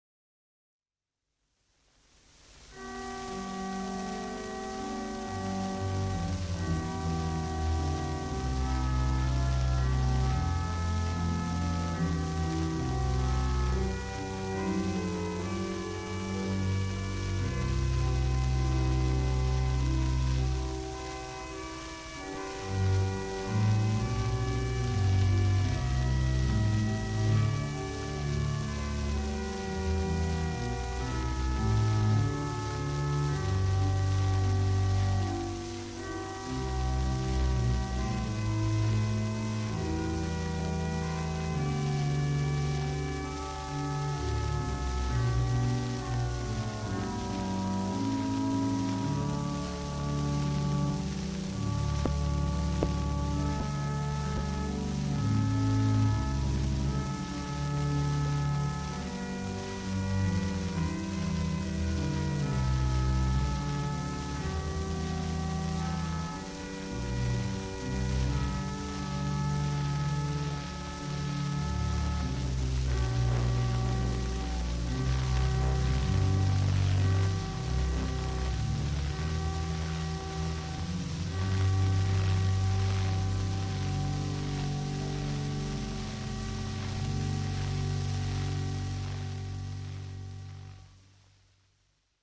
1931, disque 78 tours, 30 cm, Columbia DFX 233, Jean-Sébastien Bach :